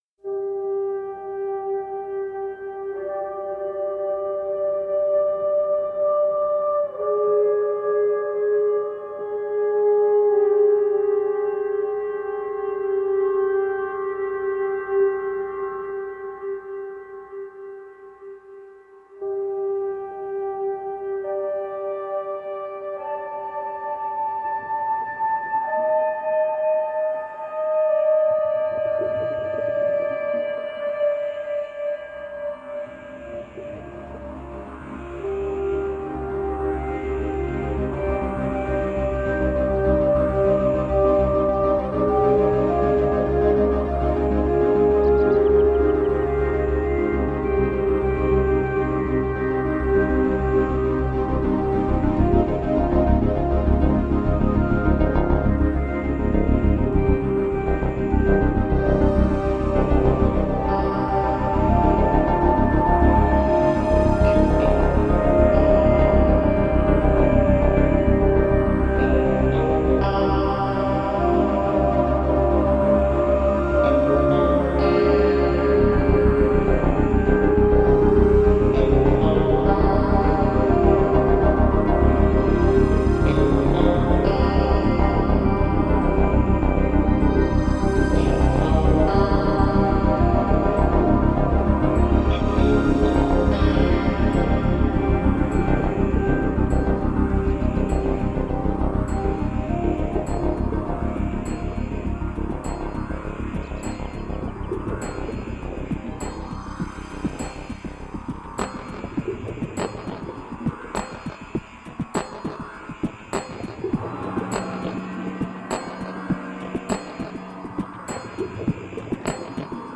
Electronica
Space Music
Frosty ambient with subtle acid jazz grooves